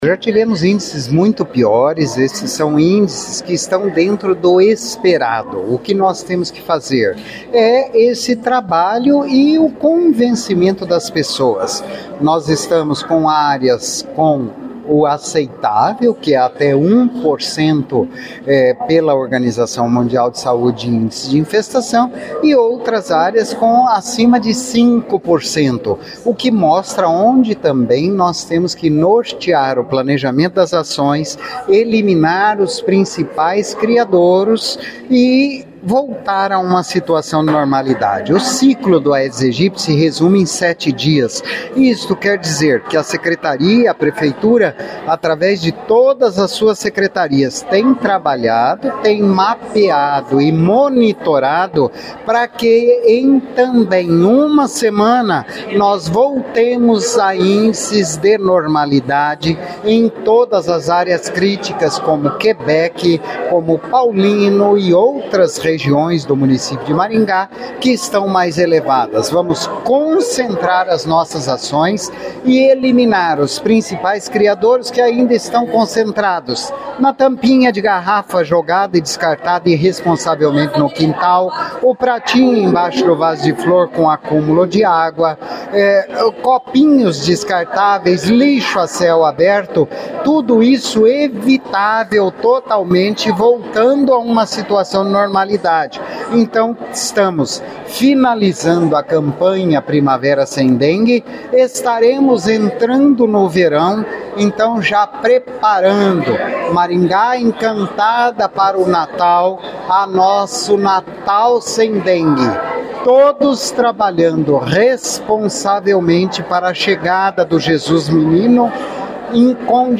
Ouça o que diz o secretário de Saúde Antônio Carlos Nardi: